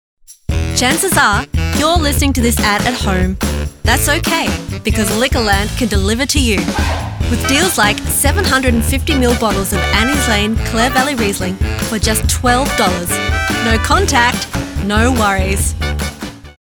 Female
English (Australian)
Yng Adult (18-29)
I provide an enthusiastic, youthful and vibrant voice over.
Radio Commercials